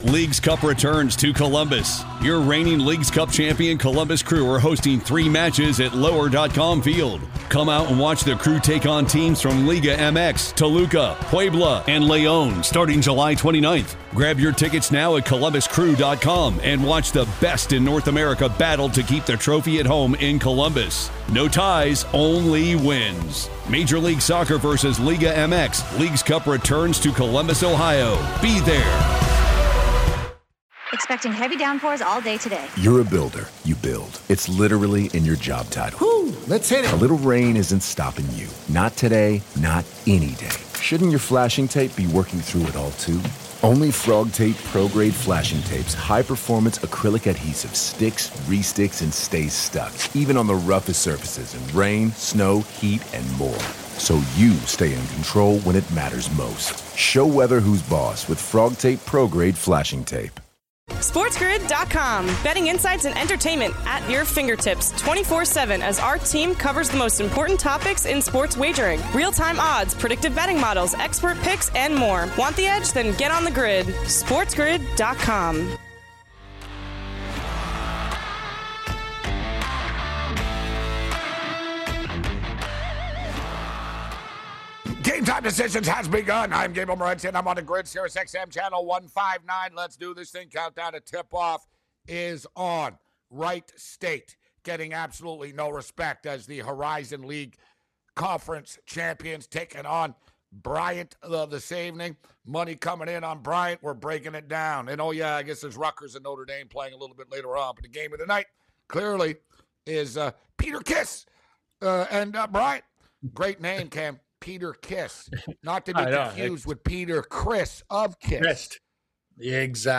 Tune in as the guys react live to more player movement across the NFL including Von Miller signing with the Buffalo Bills!